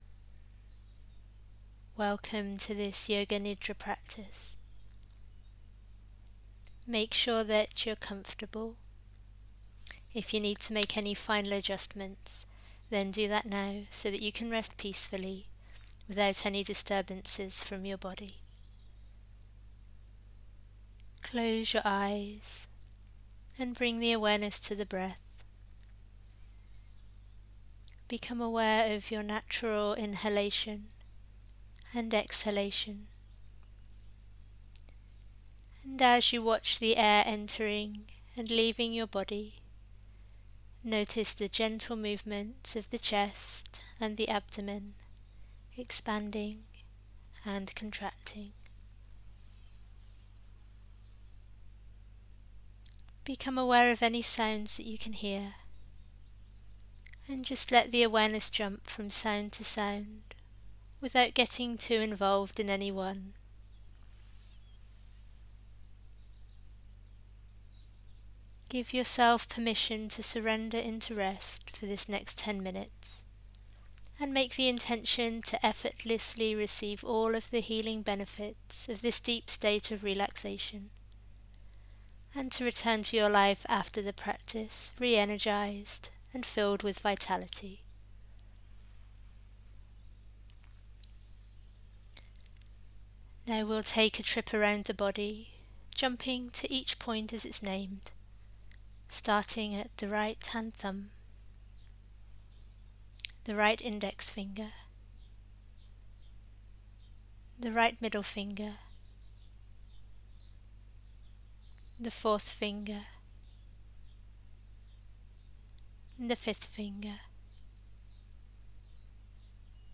Vocal Mode: Spoken word